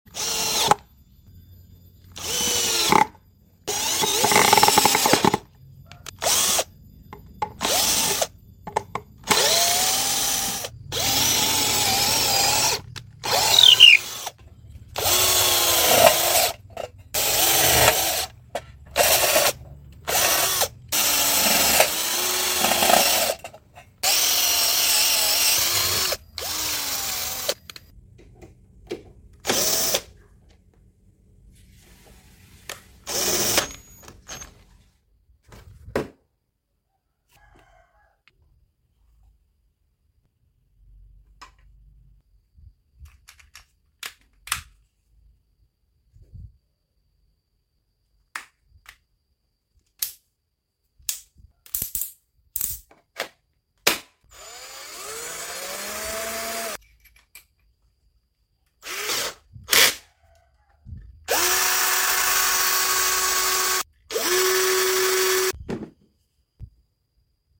Full Tes Bor Cordless JLD sound effects free download
Full Tes Bor Cordless JLD II 48VF 13mm Impact Drill
Diameter chuck 13mm, torsi 35 NM, 1750 RPM, bisa bolak-balik, ada pengaturan torsi, speed 1 dan 2.